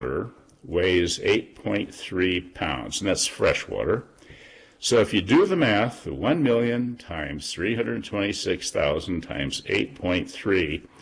Lake Almanor Basin Public Service Radio